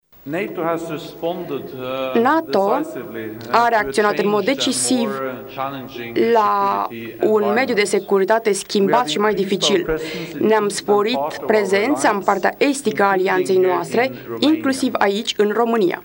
După întrevedere, cei doi oficiali au susținut o conferință de presă comună.